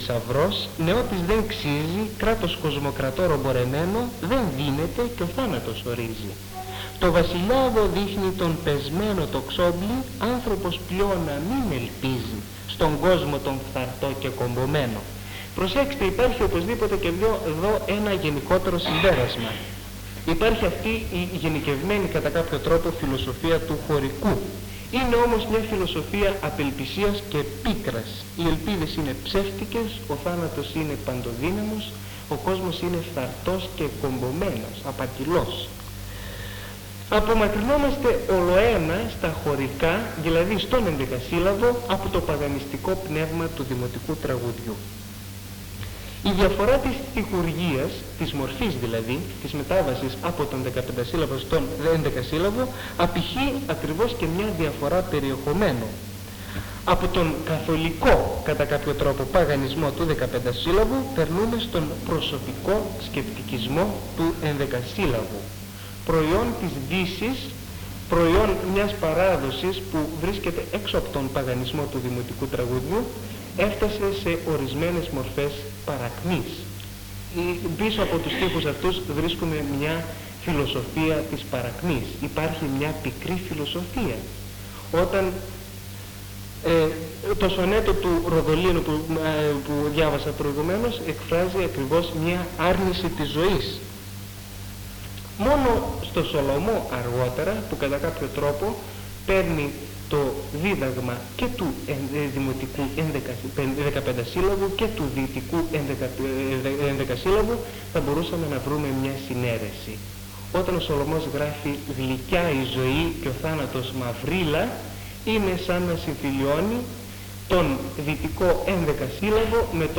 Περιγραφή: Κύκλος Μαθημάτων